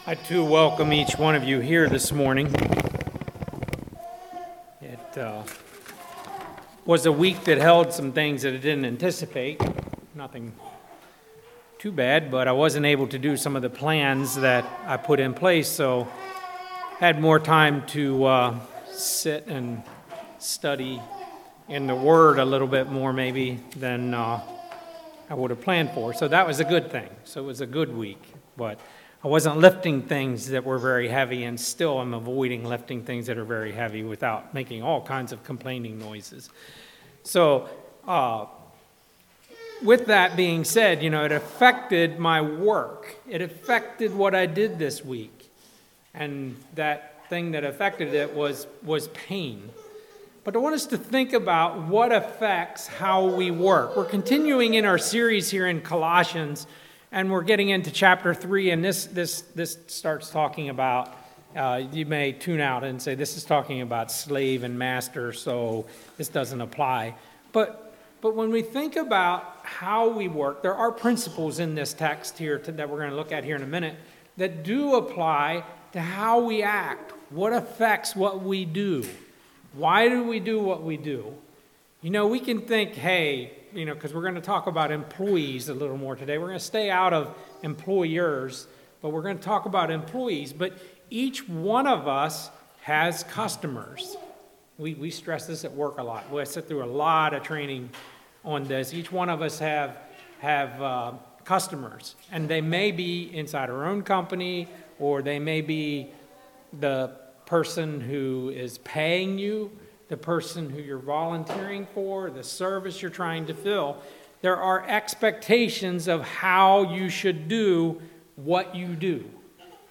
Colossians series Passage: Colossians 3:22-25 Service Type: Message « The Order of the Christian Family